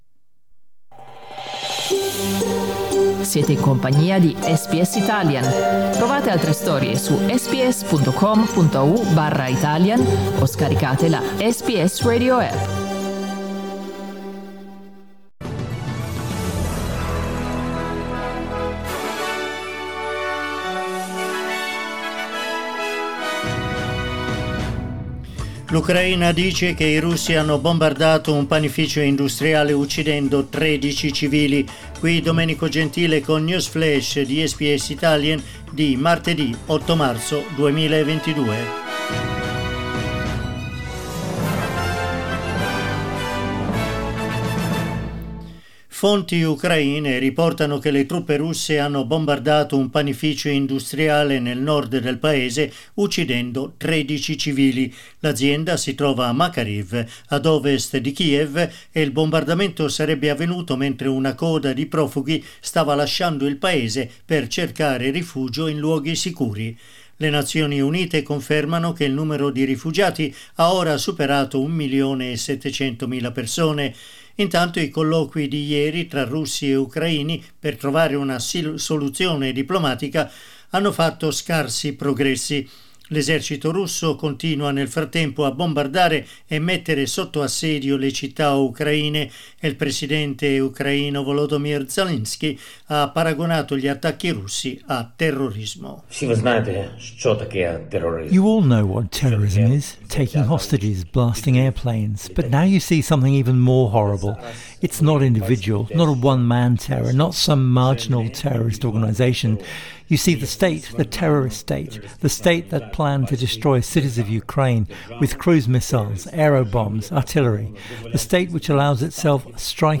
News flash martedì 8 marzo 2022